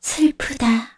Kara-Vox_Sad2_kr.wav